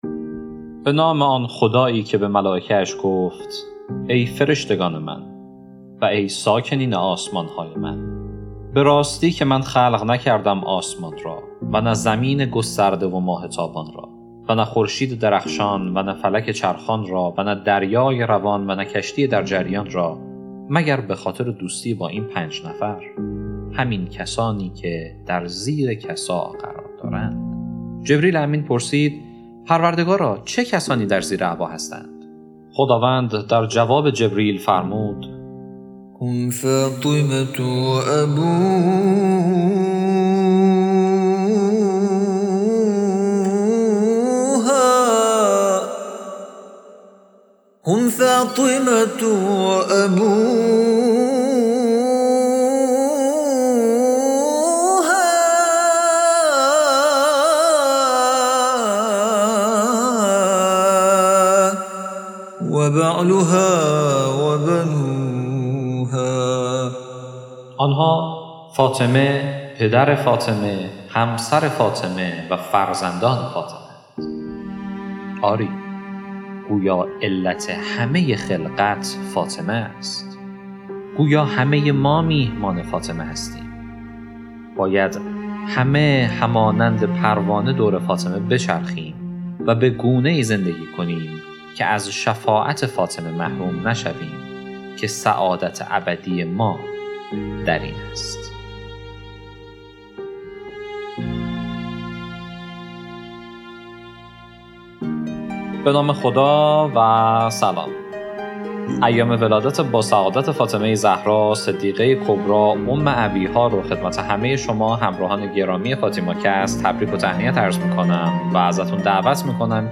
پادکست صوتی داستان هفته پنجم